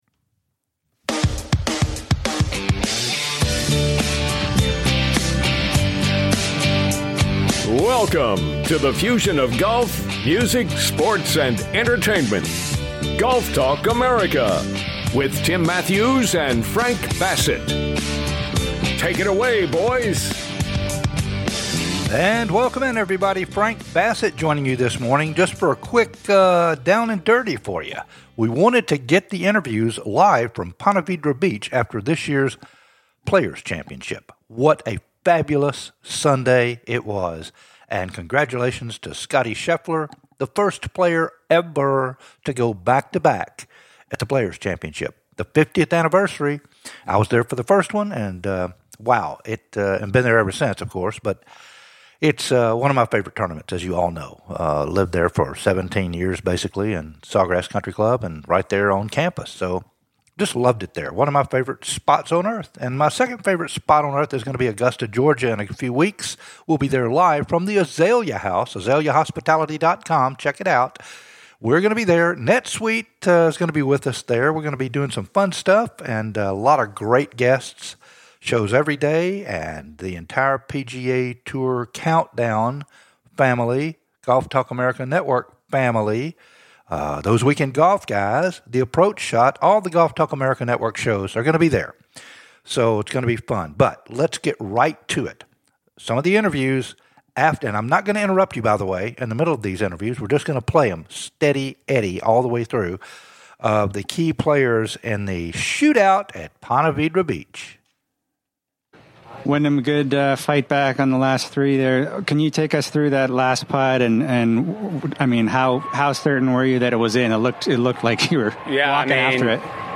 Hear fro the winner and the defeated "LIVE" from The 2024 PLAYERS CHAMPIONSHIP